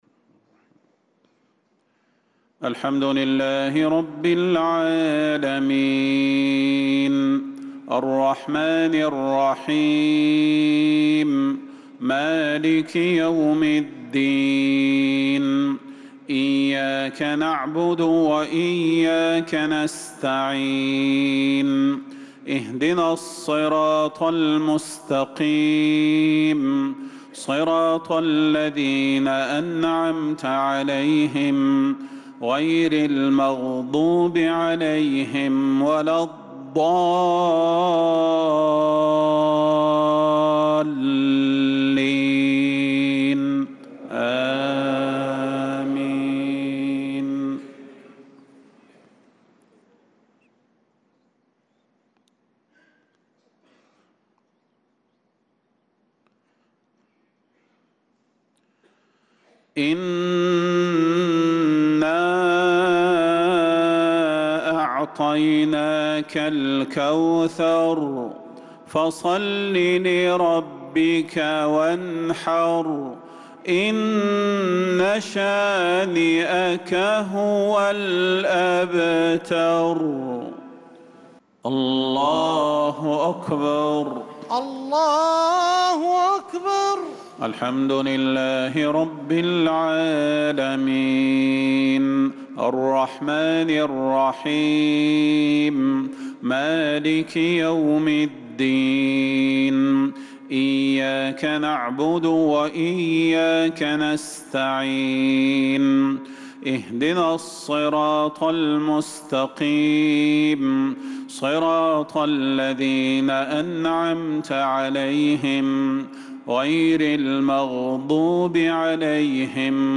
صلاة المغرب للقارئ صلاح البدير 17 شوال 1445 هـ
تِلَاوَات الْحَرَمَيْن .